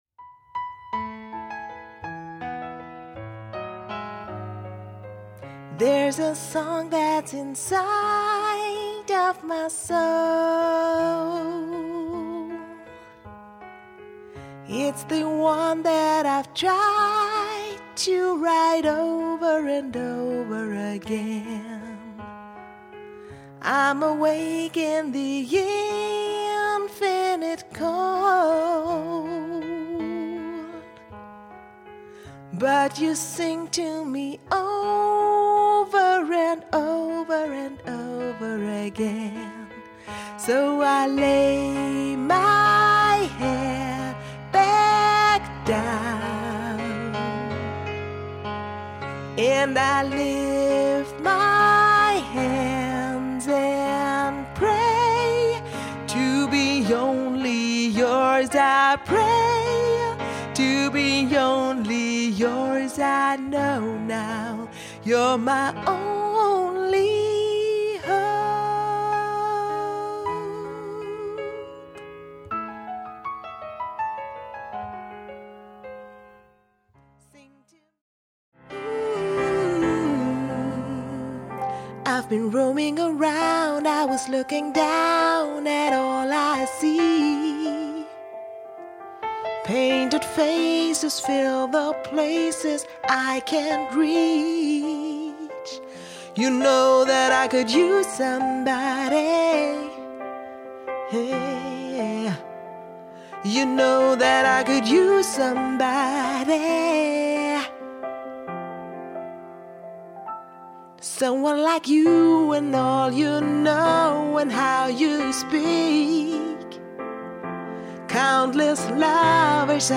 Pop Ballades & Disco, Demos Live & Unedited: